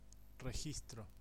Ääntäminen
US : IPA : [ˌɑb.zɚˈveɪ.ʃən]